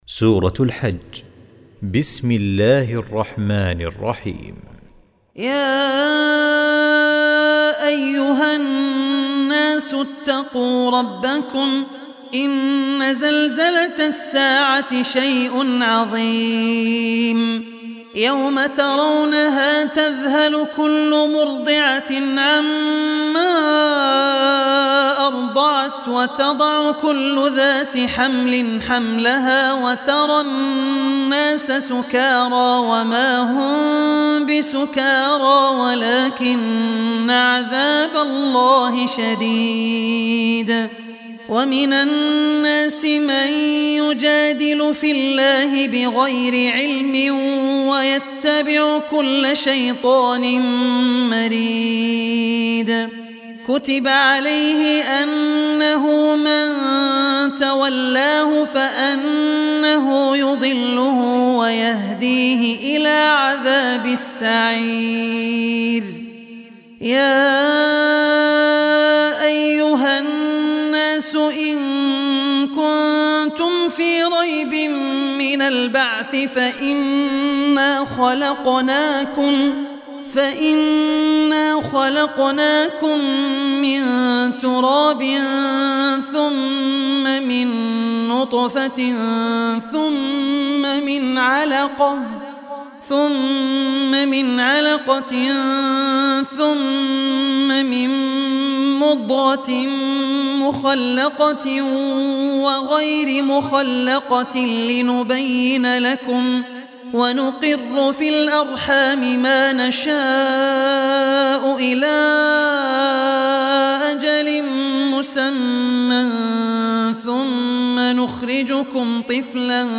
Surah Hajj Recitation by Abdul Rehman Al Ossi
Surah Hajj, listen online mp3 tilawat / recitation in Arabic in the voice of Sheikh Abdul Rehman Al Ossi.